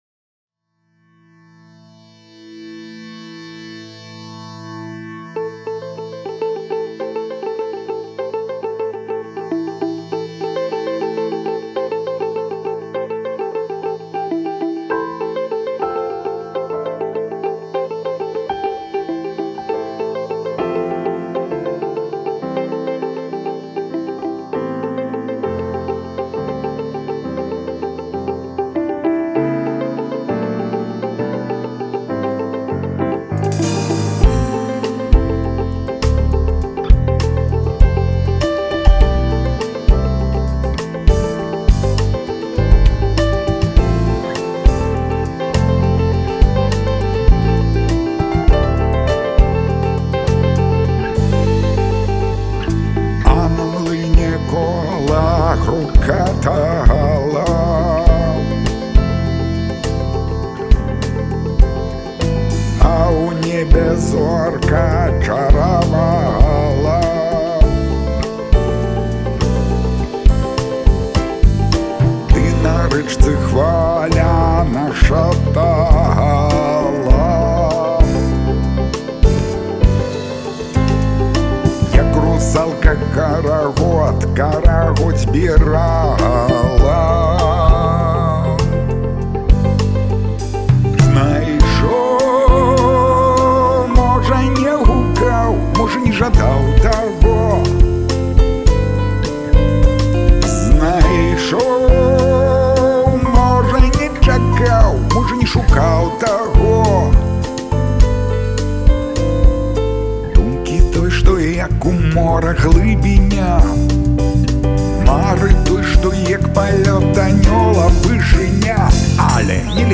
ў джазавай апрацоўцы